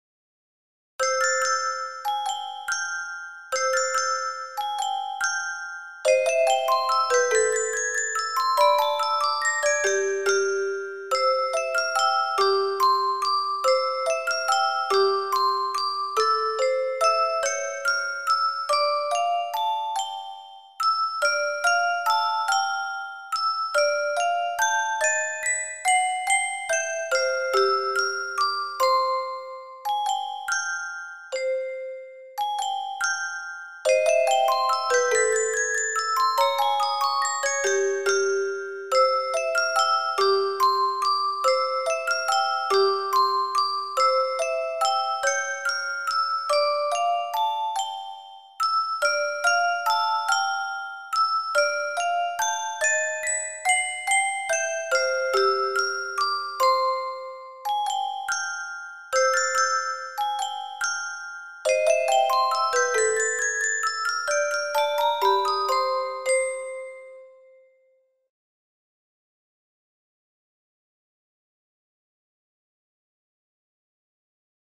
MP3オルゴール音楽素材
オルゴール チェレスタ ミュージックボックス